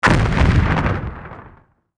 OtherDestroyed5.wav